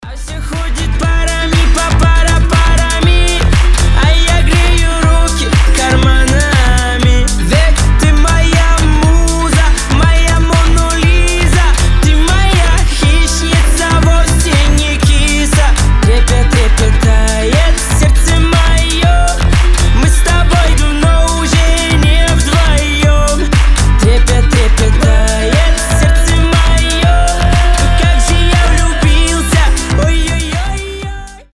• Качество: 320, Stereo
гитара
мужской голос